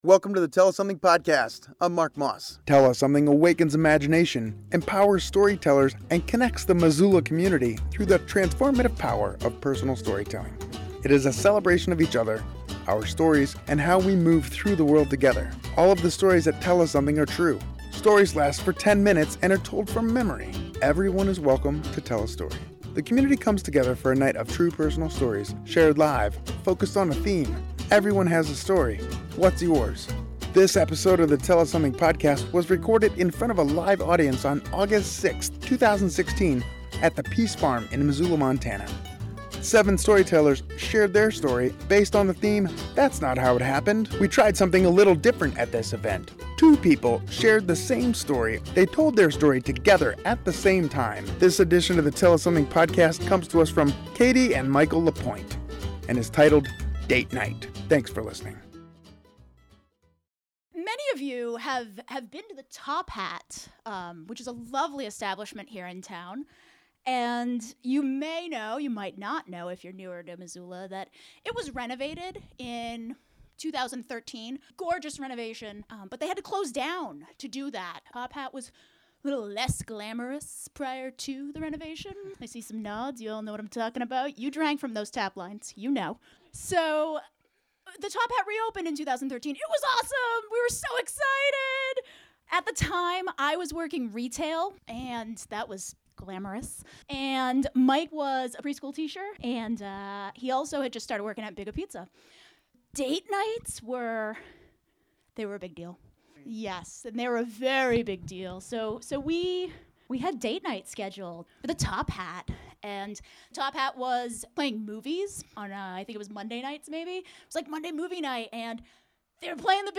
This episode of Tell Us Something was recorded in front of a live audience on August 6, 2016, at The PEAS Farm in Missoula, MT. 7 storytellers shared their story. different at this event.